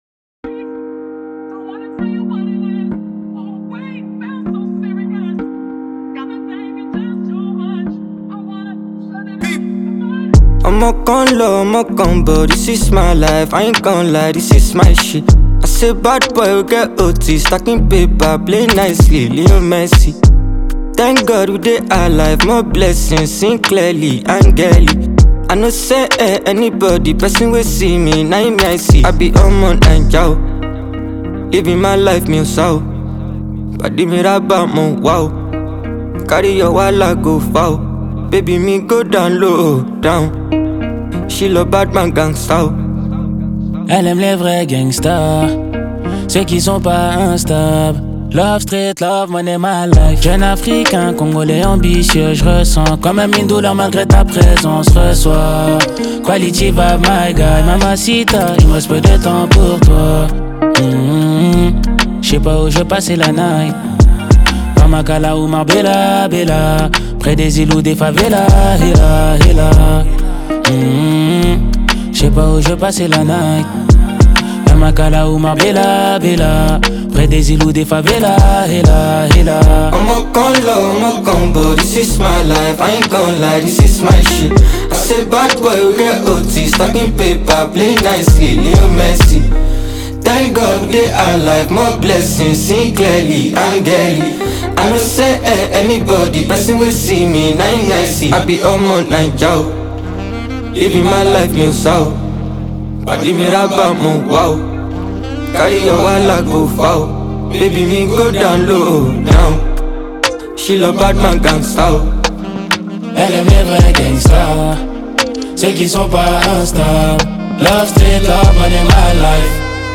pulsating new collaboration
a blend of Fuji influences, street-hop, and modern Afrobeats
gritty anthem